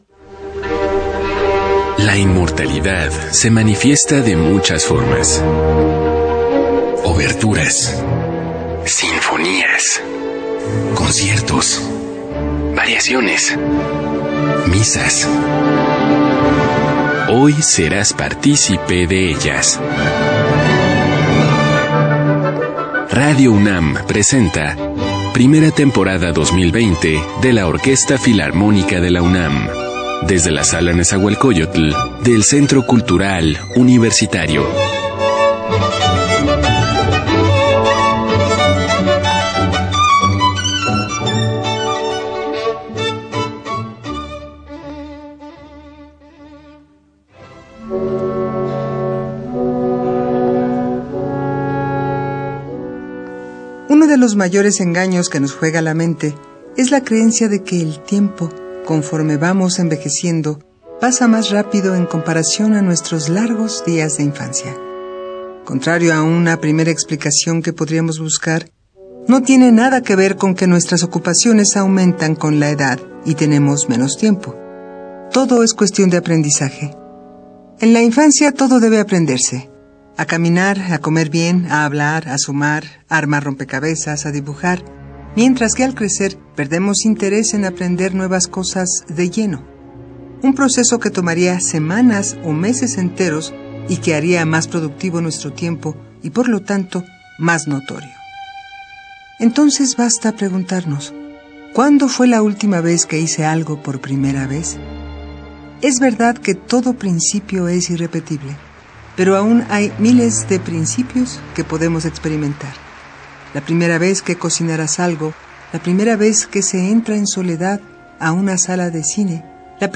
Concierto OFUNAM, Programa no.4 de la primera temporada 2020. Celebrando 250 años de Ludwig van Beethoven
archivosonoro-ofunam-programa-4-primera-temporada-2020.mp3